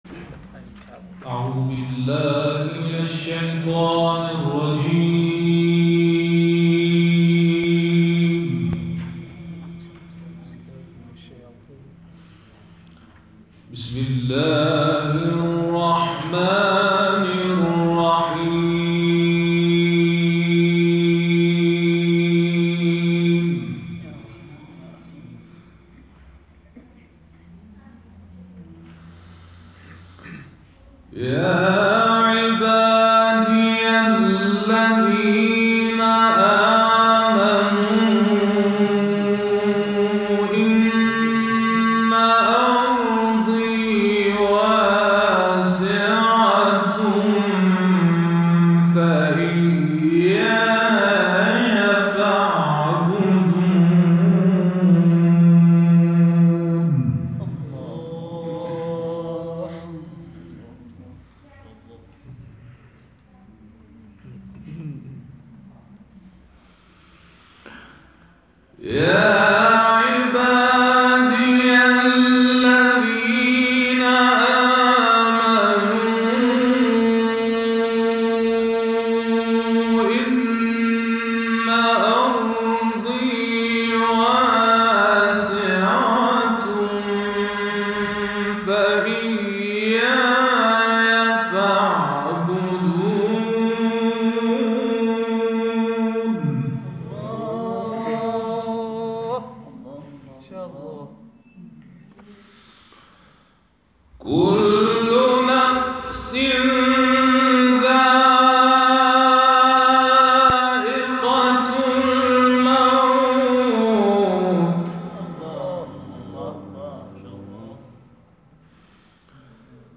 تلاوت سوره عنکبوت
این تلاوت کوتاه در فروردین ماه سال 1396 اجرا شده و مدت زمان آن 17 دقیقه است.